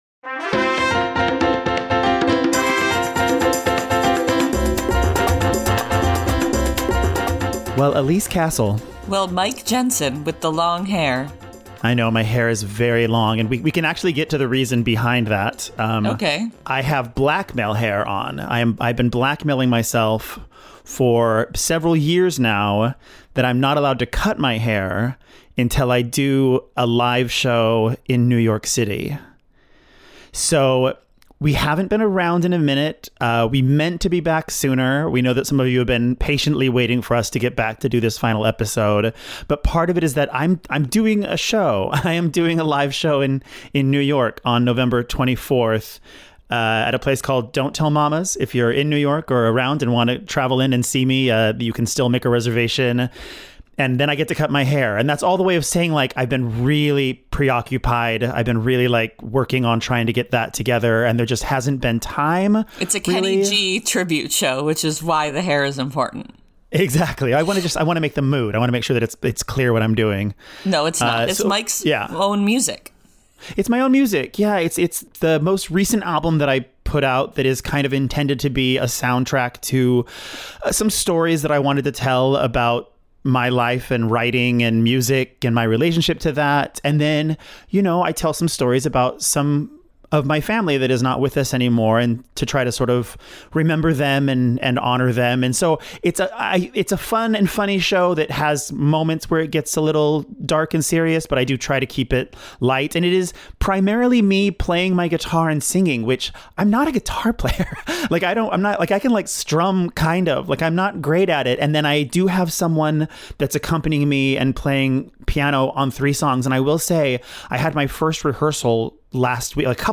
No clips, just conversation.